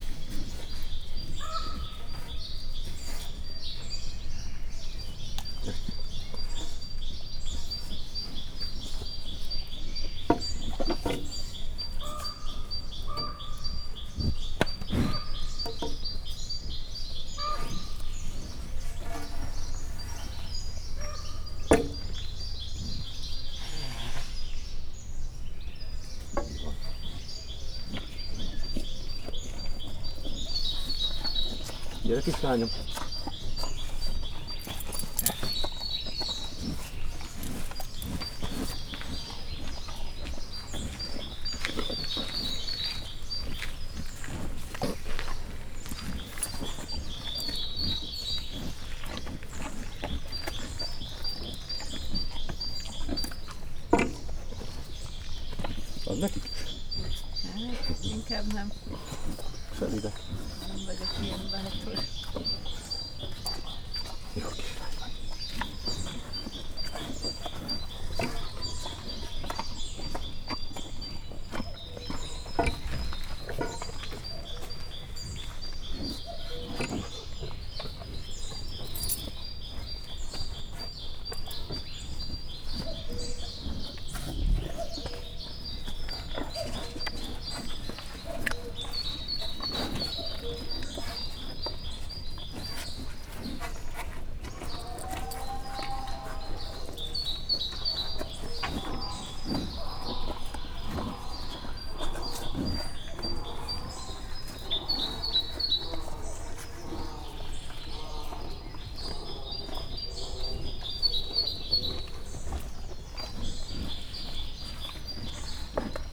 madardalosreggeli_miskolczoo0156.WAV